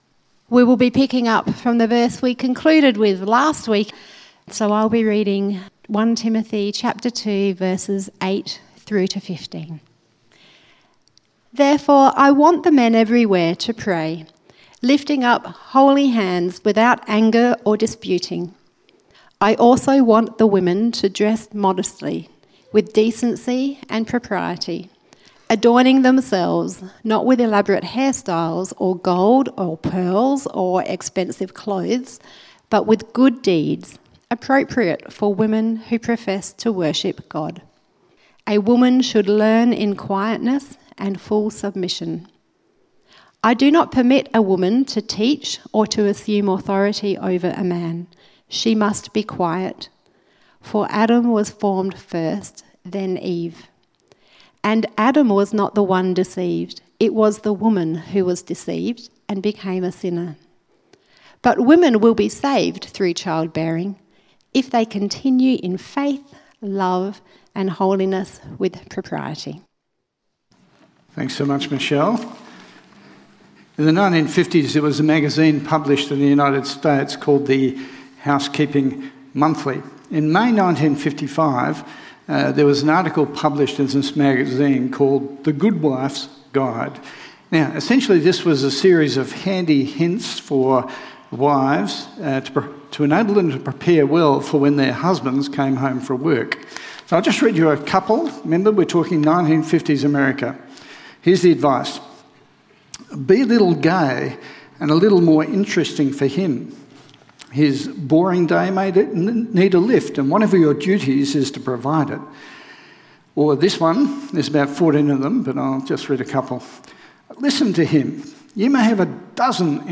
1 Timothy Sermon outline